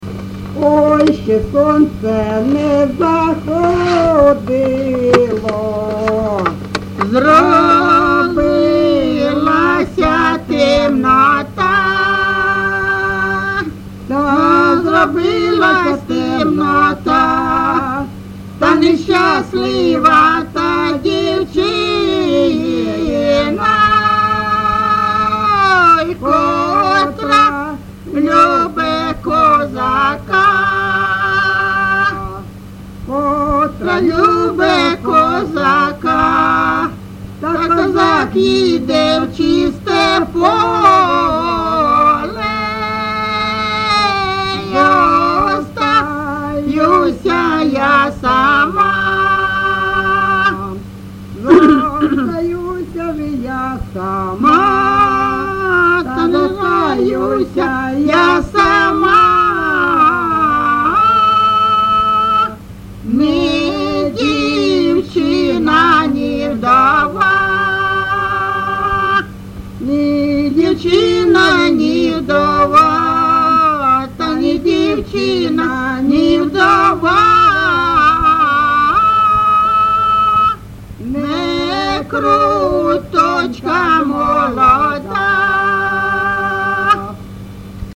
ЖанрПісні з особистого та родинного життя, Рекрутські
Місце записус. Калинове Костянтинівський (Краматорський) район, Донецька обл., Україна, Слобожанщина